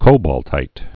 (kōbôl-tīt) also co·balt·ine (-tēn)